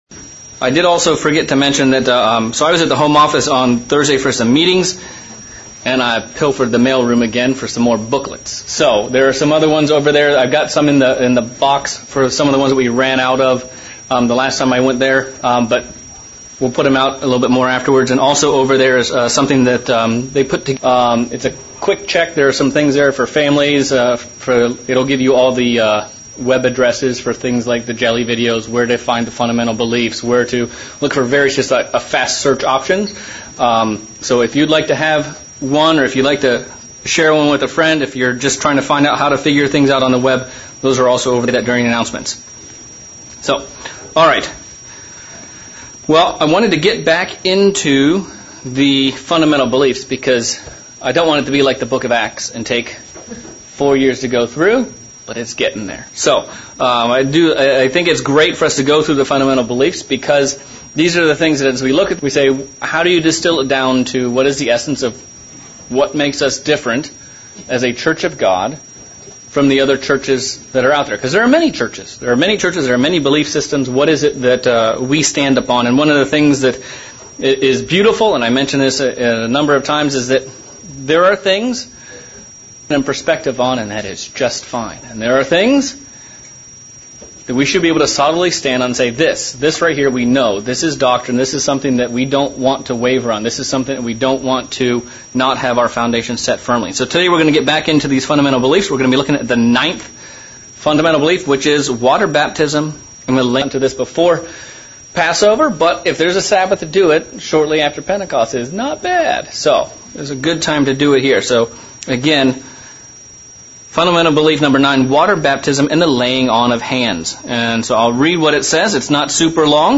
Sermon looking at our fundamental belief on baptism and why it is such and important ceremony in the Church of God and the special symbolism that goes with it.